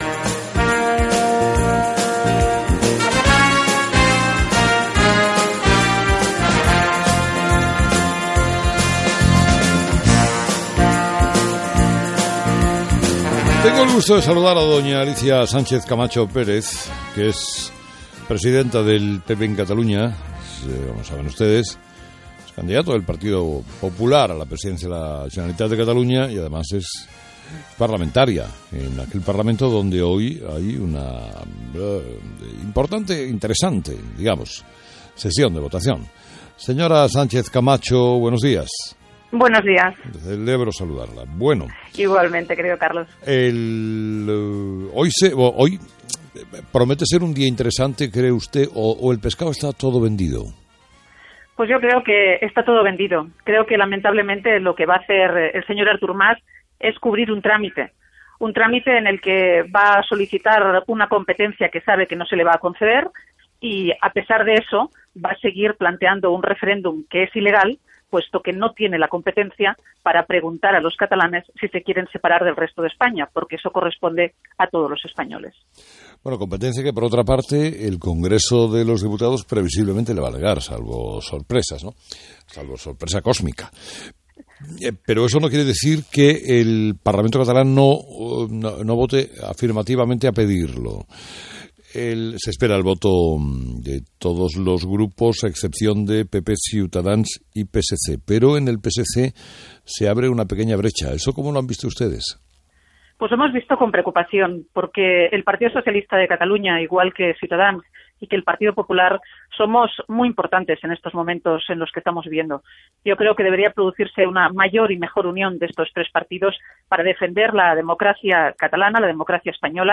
Entrevista Alicia Sánchez Camacho.